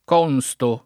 consto [ k 0 n S to ]